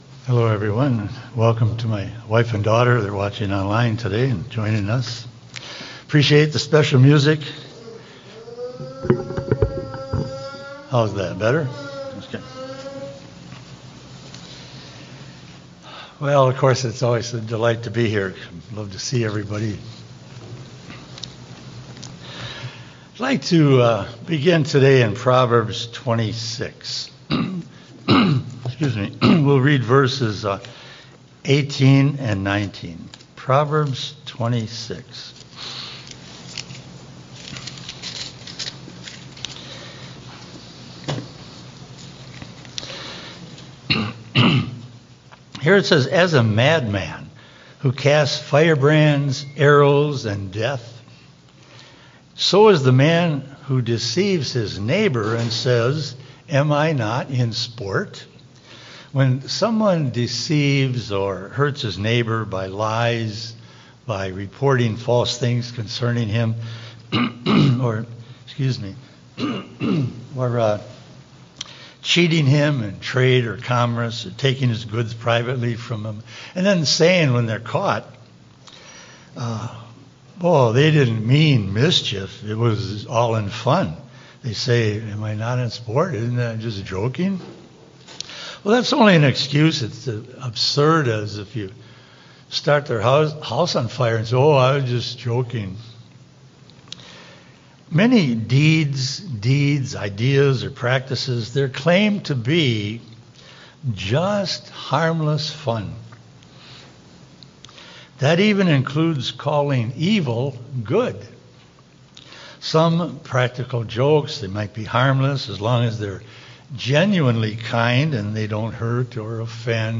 In this serious and eye-opening message, we’re urged to examine the things the world calls “harmless fun” and measure them against the truth of God’s Word. Beginning with Proverbs 26’s warning about deceiving others “in sport,” the sermon shows how easily people excuse practices rooted in falsehood, and how Satan uses traditions, holidays, and cultural customs to disguise spiritual dangers as lighthearted entertainment.